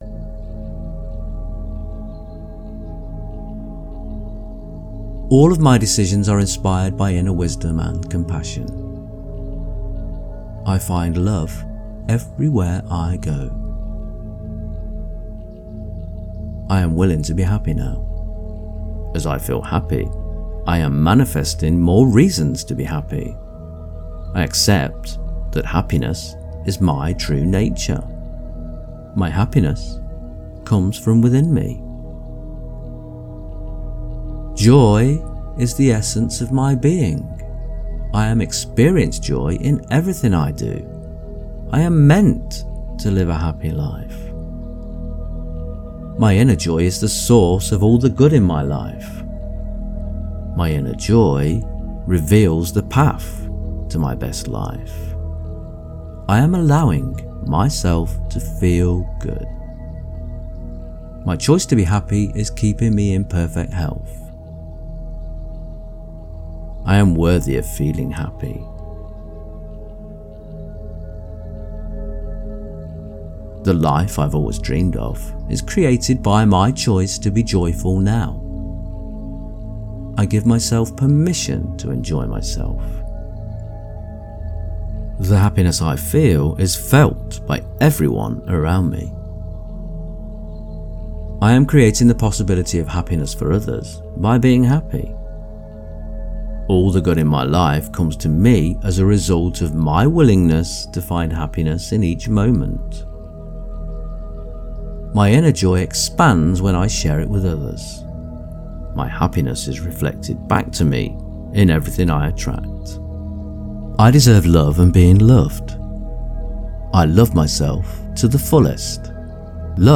This collection of 100 affirmations are tuned to the harmonious frequency of 639 Hz.
639-affirmations.mp3